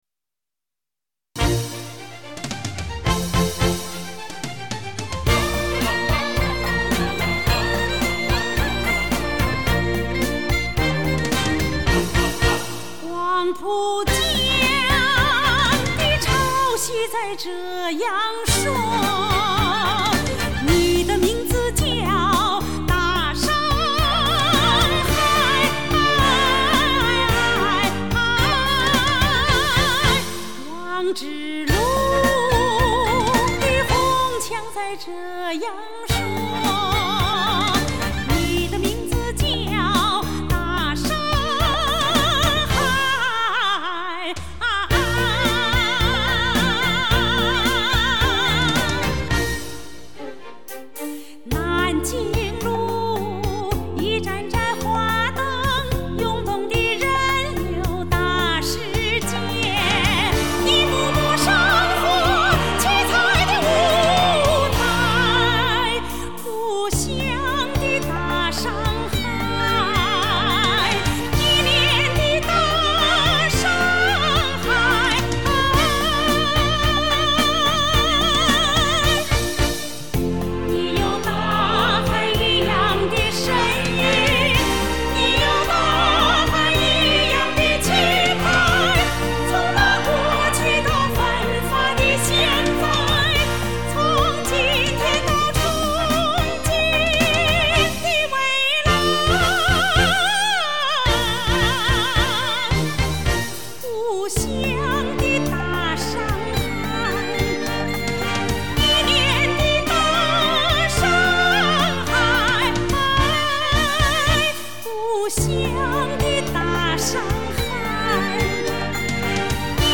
，被评选为“中国十大民族女高音歌唱家”。